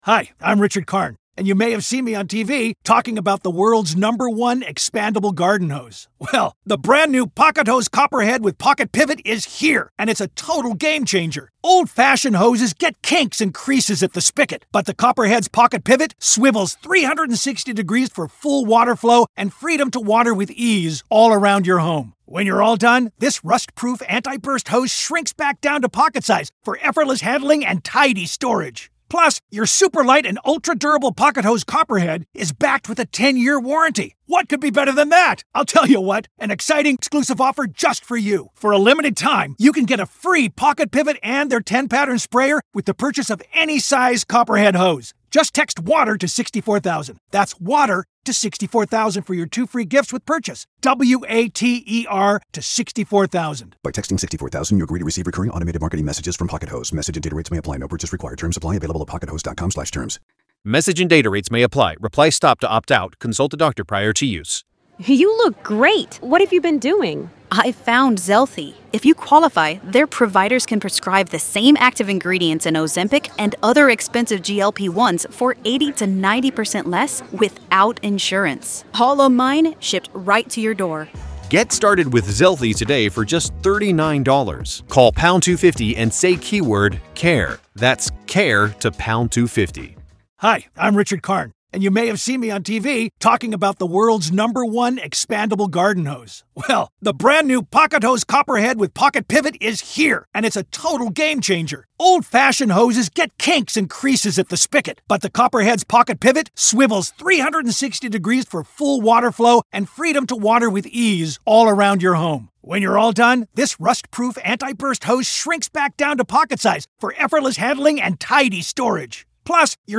From vehicle dynamics to scene interpretation, this testimony marks a pivotal moment in the battle of experts.